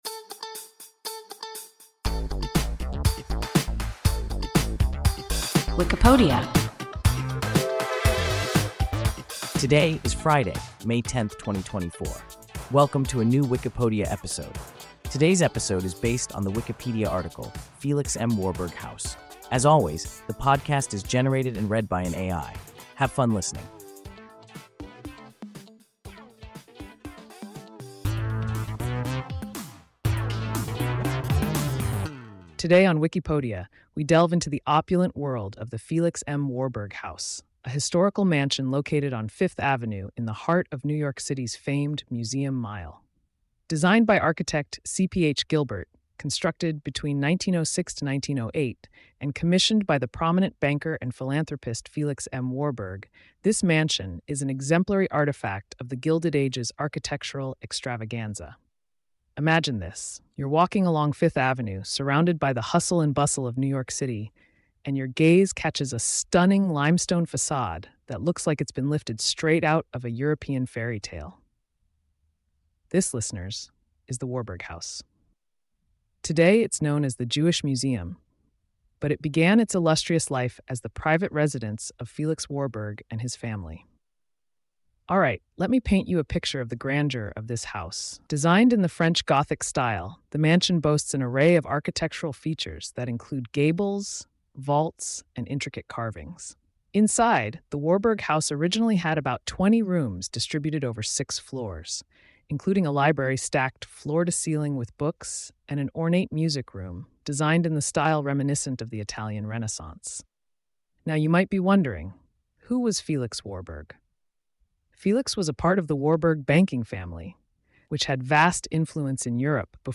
Felix M. Warburg House – WIKIPODIA – ein KI Podcast